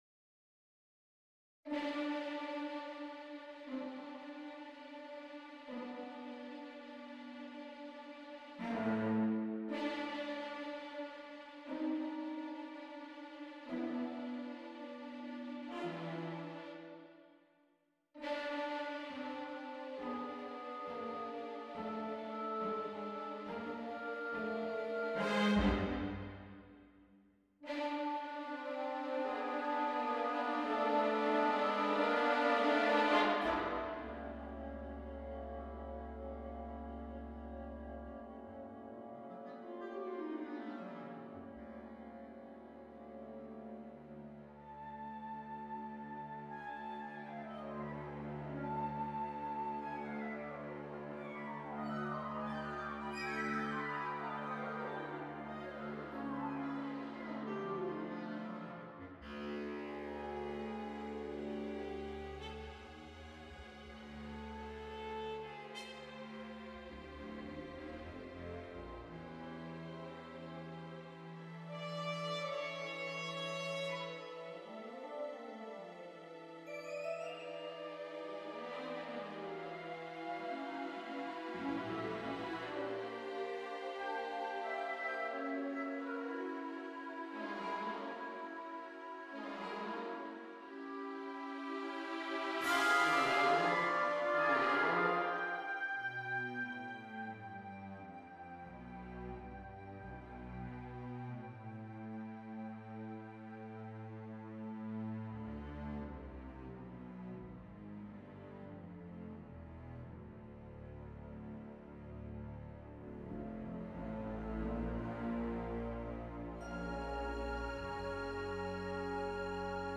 a large-scale orchestral score entitled Hir nych yr angau
offers a highly emotional memorial to a loved one.